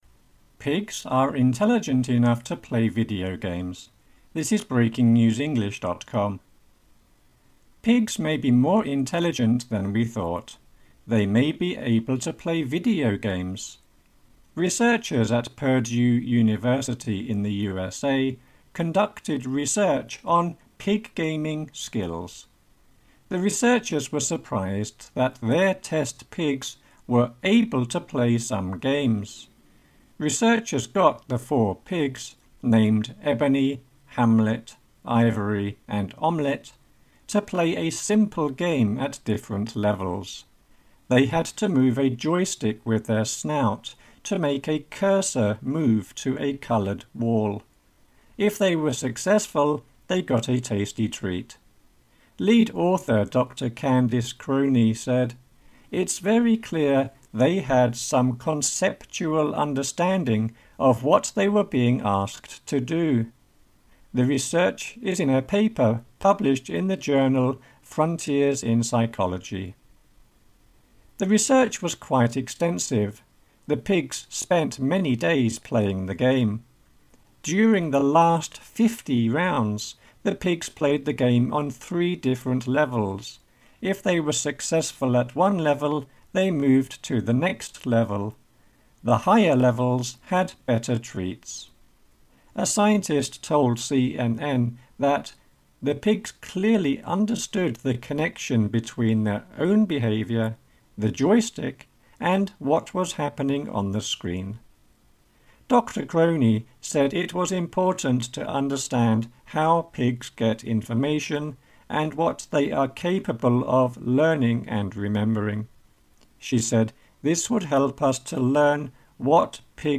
AUDIO(Slow)